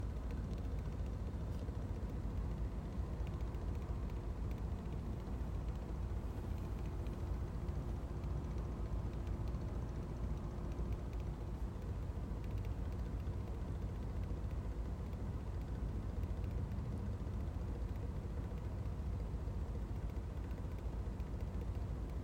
En nettoyant mes filtres ce week-end, je me suis aperçu que mon UE Mitsubishi (MSZ AP15) faisait un tout petit bruit d'hélicoptère ou de cliquetis.
- Cela s'entend surtout en vitesse minimale, car le souffle le couvre sur 2/3/4.
- Il faut être près du split (10 cm) pour l'entendre car c'est assez léger.
J'ai essayé de l'enregistrer mais il faut tendre l'oreille.
helico-4.mp3